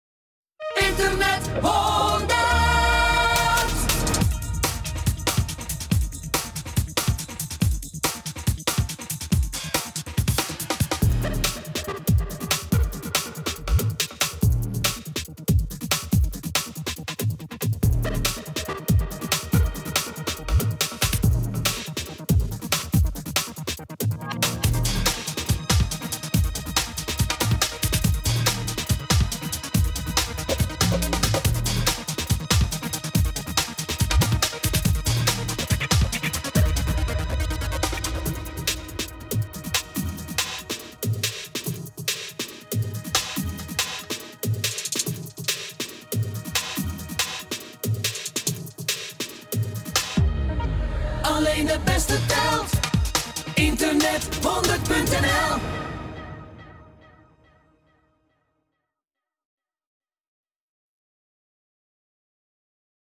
jingle (bedje)